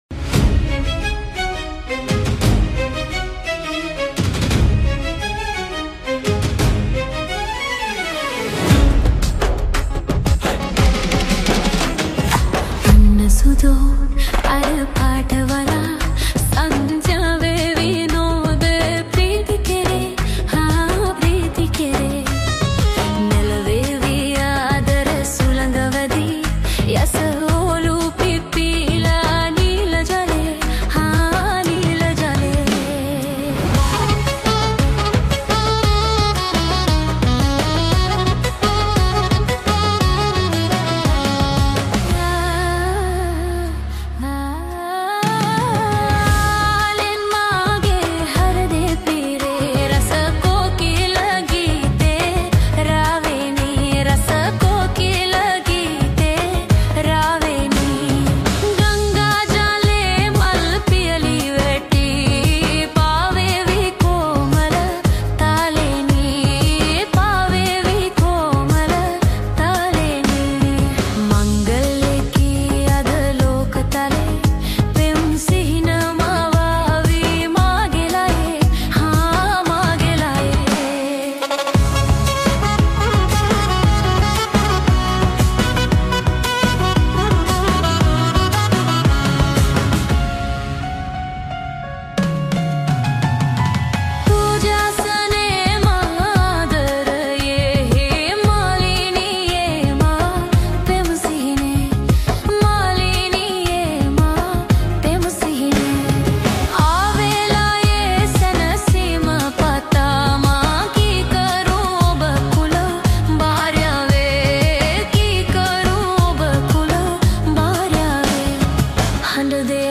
Browse more songs in Sinhala Remake Songs.